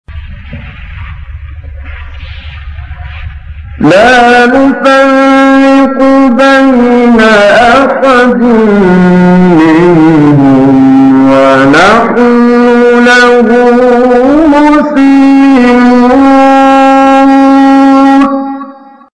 15 فراز از «کامل یوسف» در مقام بیات
گروه شبکه اجتماعی: فرازهای صوتی از کامل یوسف البهتیمی که در مقام بیات اجرا شده است، می‌شنوید.